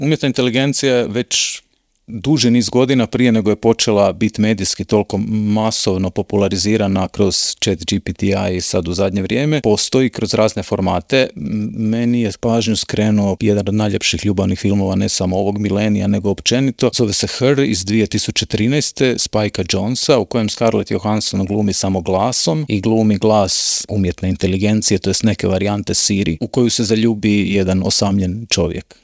Povodom najave filma, ugostili smo ga u Intervjuu Media servisa, te ga za početak pitali u kojoj je fazi film naziva BETA.